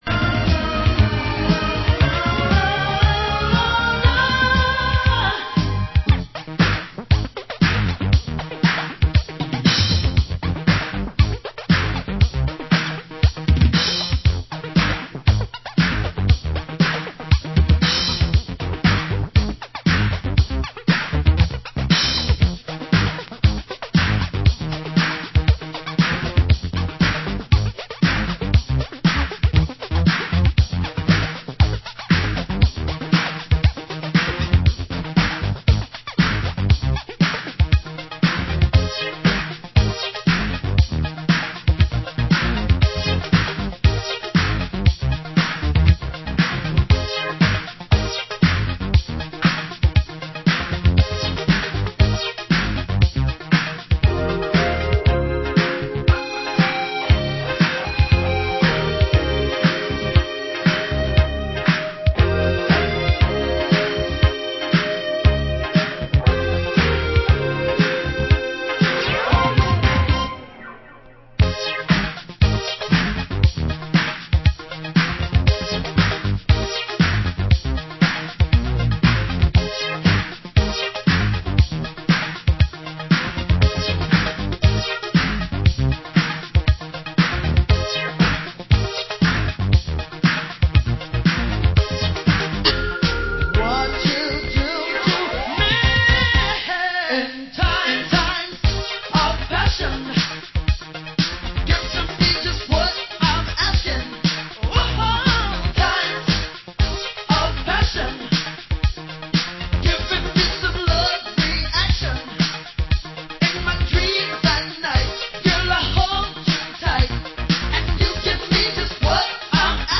Format: Vinyl 12 Inch
Genre: Disco